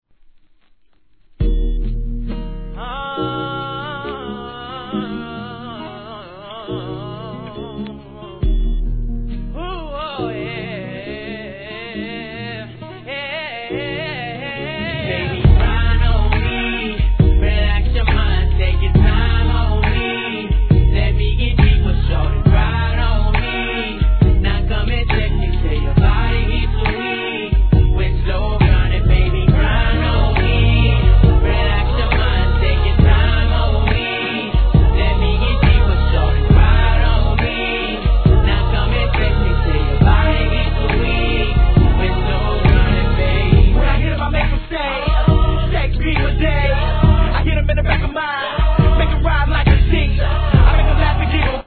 HIP HOP/R&B
胸キュンな美メロっぷりを披露した極上SLOW JAM!!